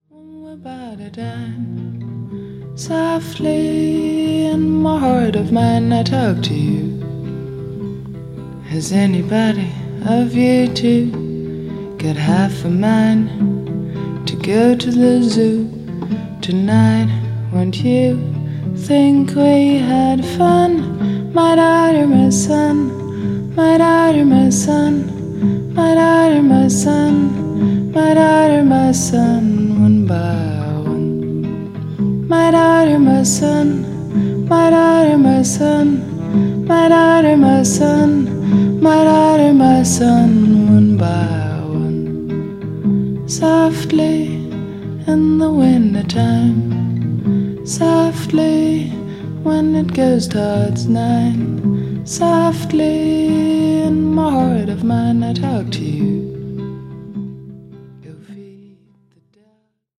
プライベート録音ならではの虚飾のない、くすんだトーンが聞くものの心に沁み入るような14曲。